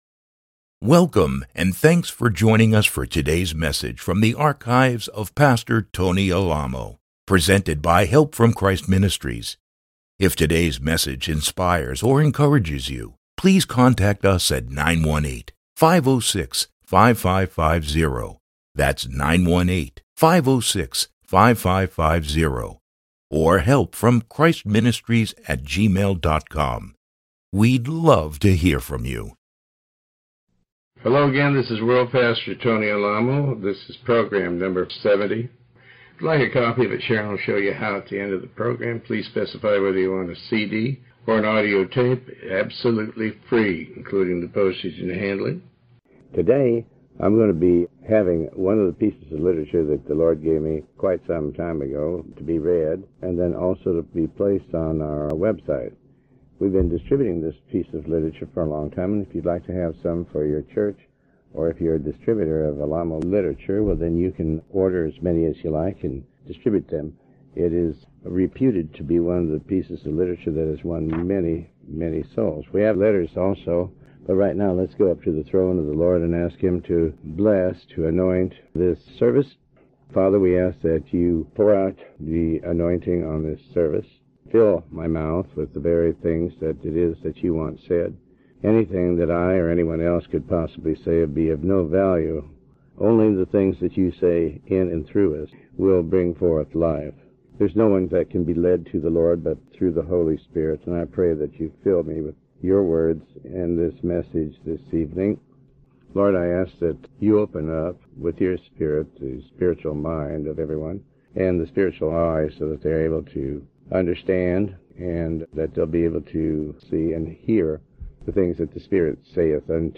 Sermon 70A